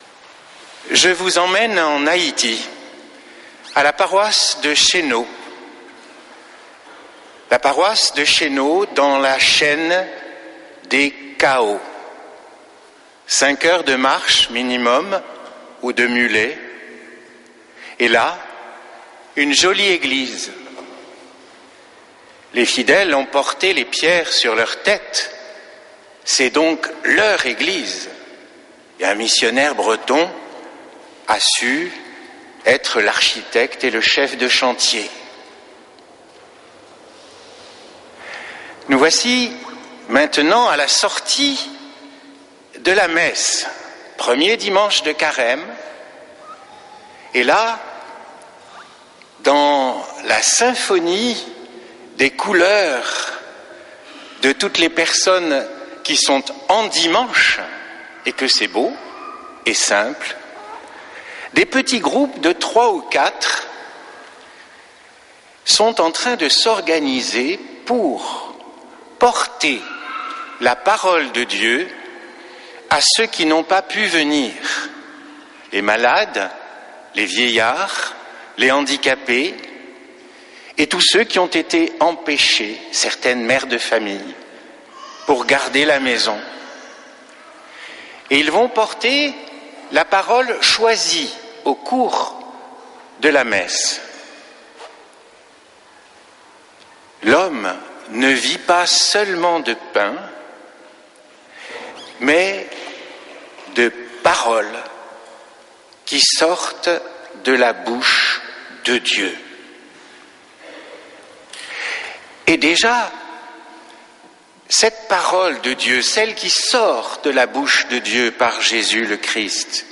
dimanche 22 février 2026 Messe depuis le couvent des Dominicains de Toulouse Durée 01 h 28 min